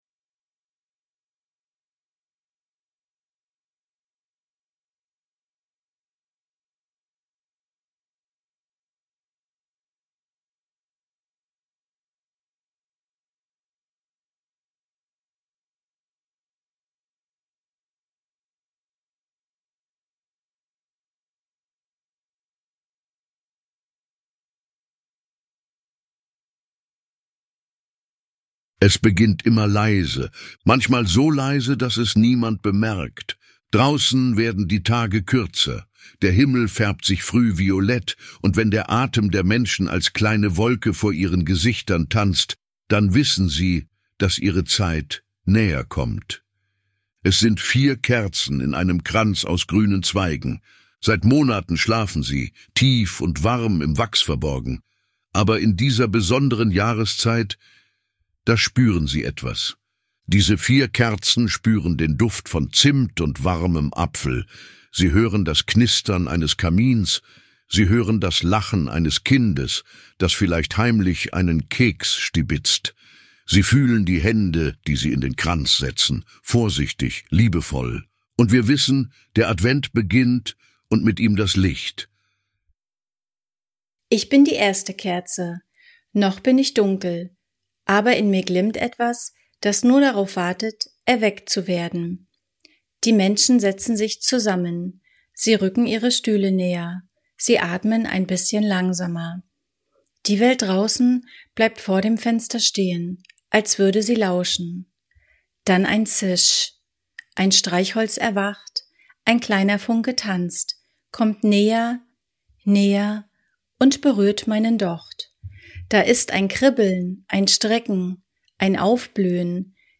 In dieser besonderen Geschichte erwachen vier Kerzen zum Leben – jede mit einer eigenen Stimme, einem eigenen Gefühl und einer eigenen Botschaft. Gemeinsam erzählen sie vom Licht der Hoffnung, des Vertrauens, der Liebe und des Friedens.